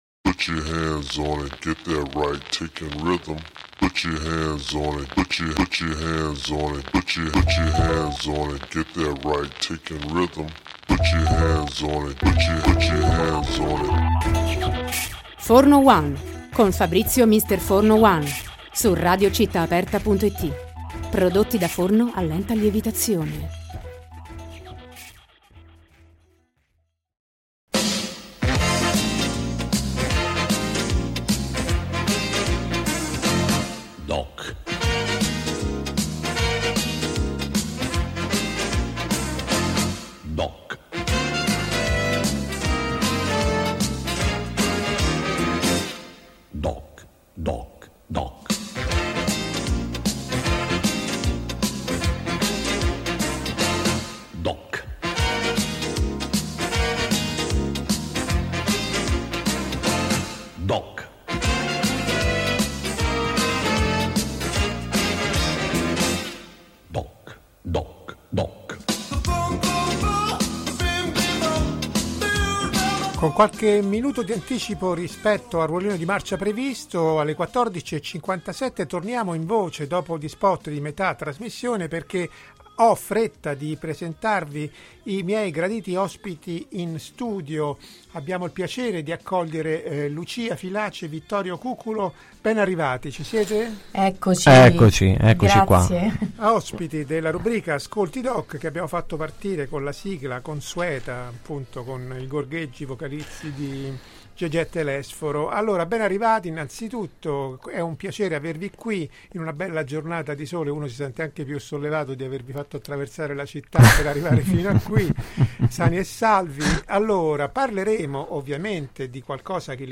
Dopo averli ascoltati, si è passato all’intervista vera e propria, chiusasi con l’ascolto di un estratto da questo nuovo album.